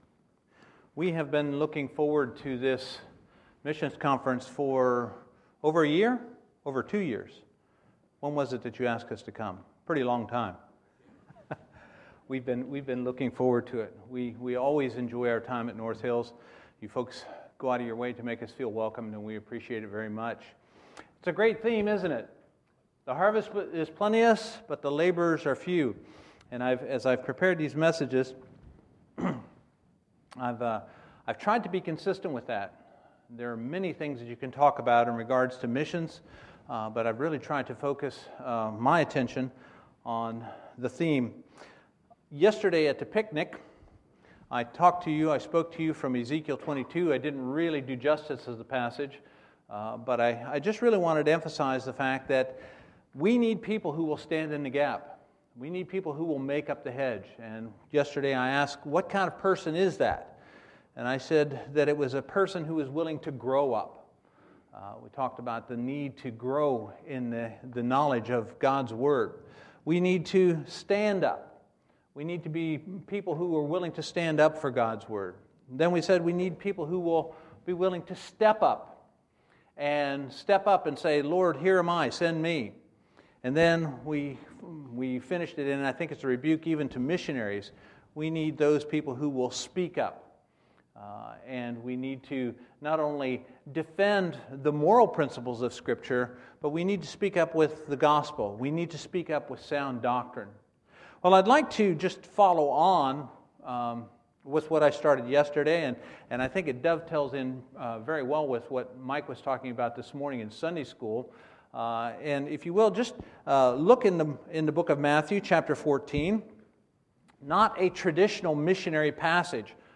Sunday, September 27, 2015 – Missions Conference Sunday Morning Service